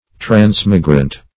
Meaning of transmigrant. transmigrant synonyms, pronunciation, spelling and more from Free Dictionary.
Search Result for " transmigrant" : The Collaborative International Dictionary of English v.0.48: Transmigrant \Trans"mi*grant\, a. [L. transmigrans, p. pr.